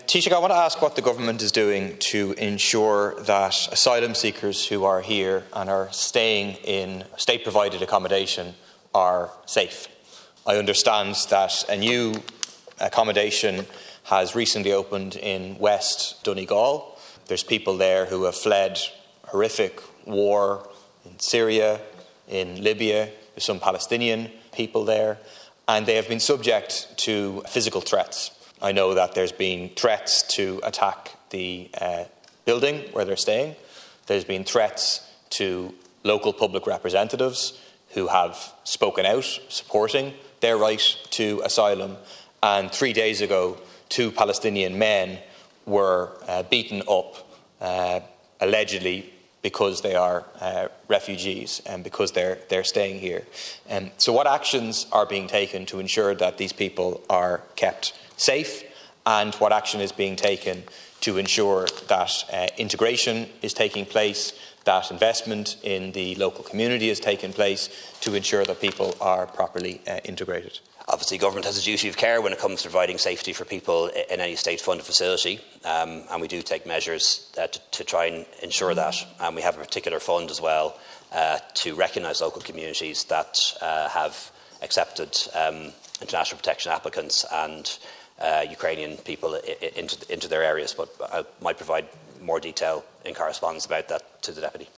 In the Dail, Deputy Paul Murphy pressed Taoiseach Leo Varadkar to outline what steps were being taken to ensure the safety of people integrating into communities: